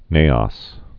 (nāŏs)